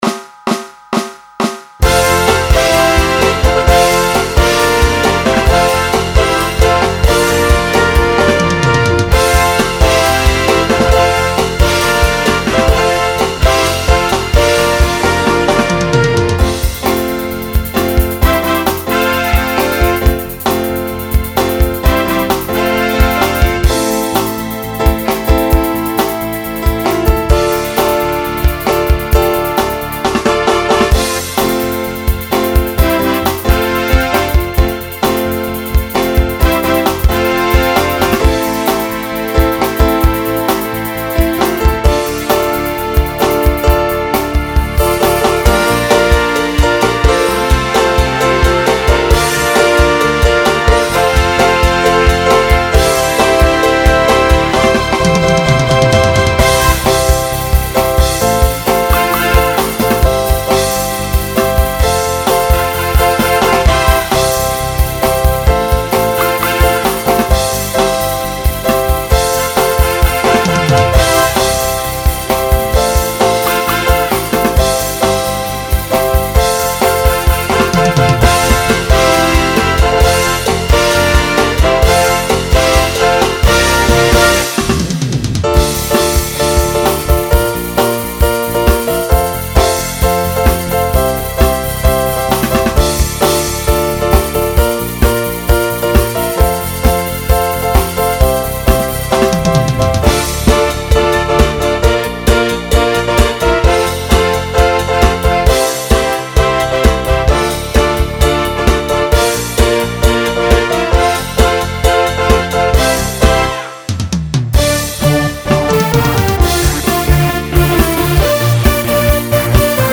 SATB Instrumental combo
Pop/Dance